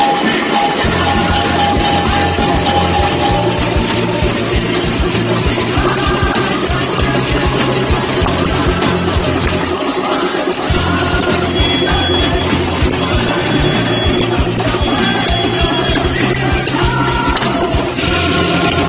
网友拍摄和提供的视频显示，当地时间2022年4月28日晚，上海各小区居民敲锅高唱国际歌。